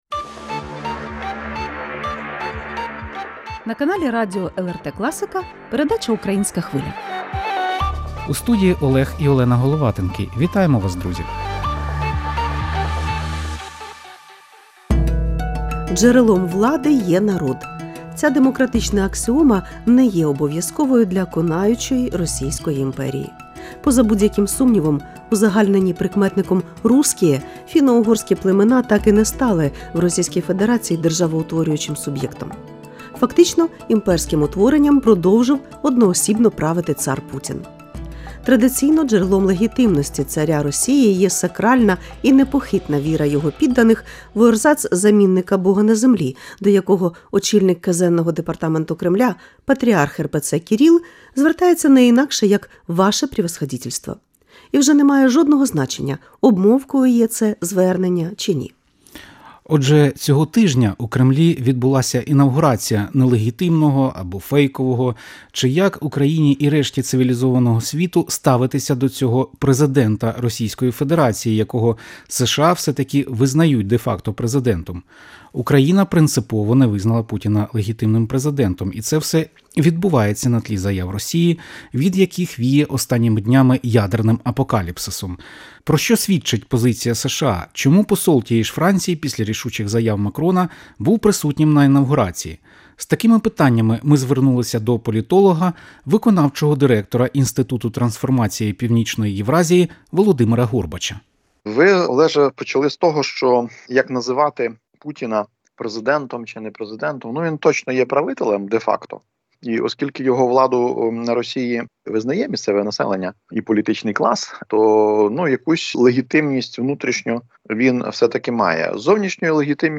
Коментар політолога